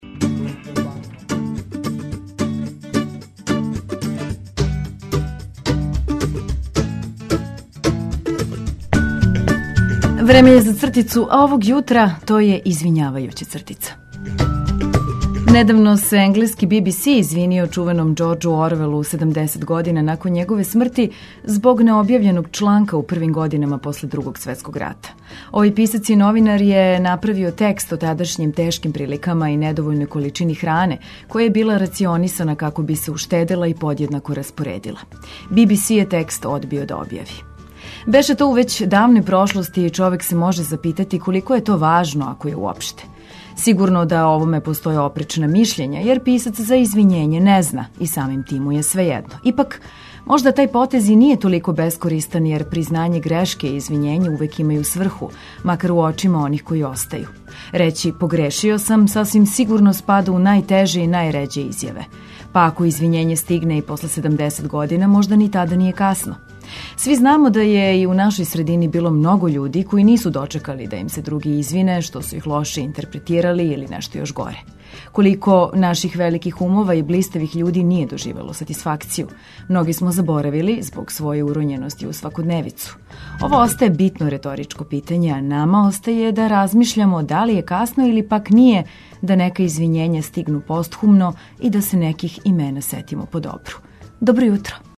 Поздравићемо дан уз добру музику, лепо расположење и важне информације.